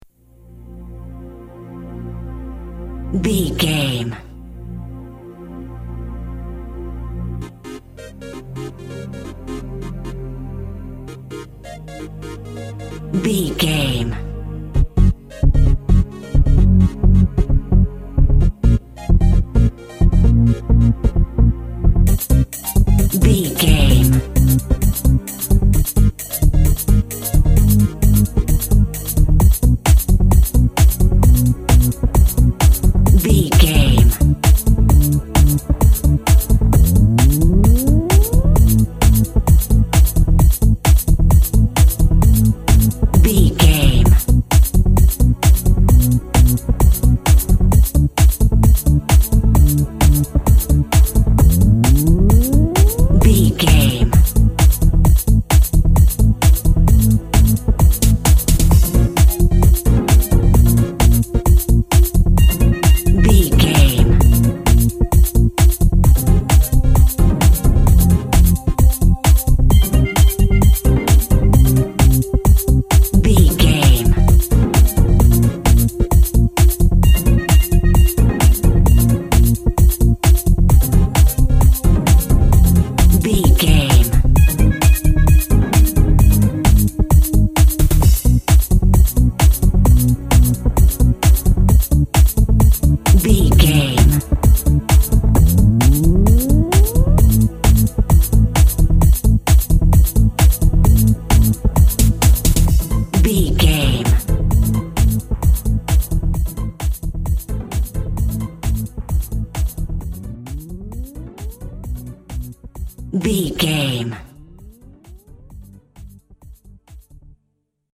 Atonal
B♭
groovy
happy
futuristic
uplifting
strings
drum machine
techno
trance
synth lead
synth bass
Synth Pads